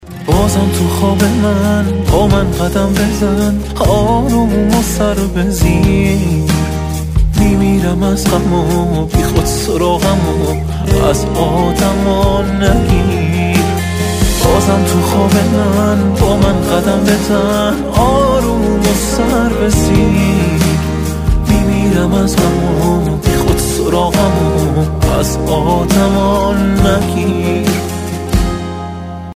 زنگ موبایل عاشقانه و با کلام